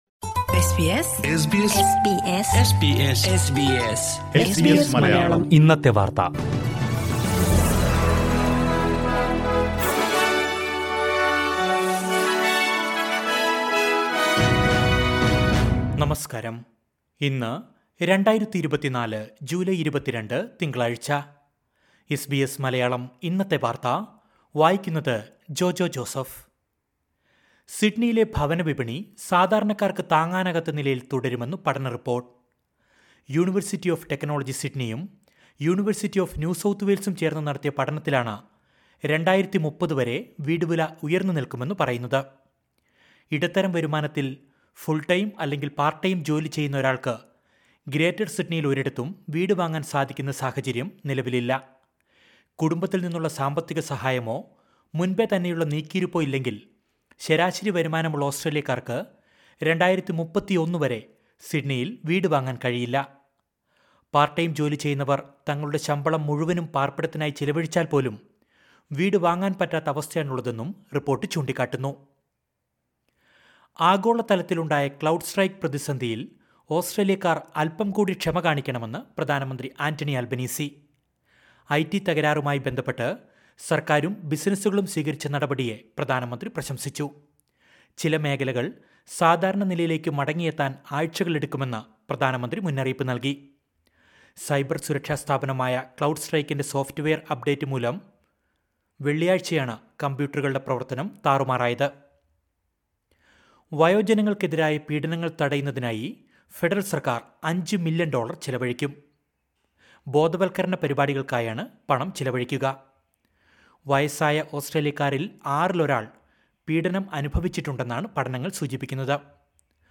2024 ജൂലൈ 22ലെ ഓസ്‌ട്രേലിയയിലെ ഏറ്റവും പ്രധാന വാര്‍ത്തകള്‍ കേള്‍ക്കാം...